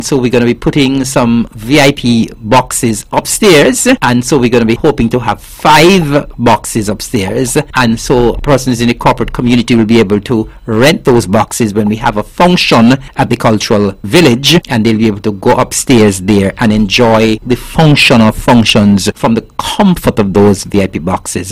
Deputy Premier and Minister of Culture, Hon. Eric Evelyn gave updates regarding the project, initiated by feedback from the relevant stakeholders.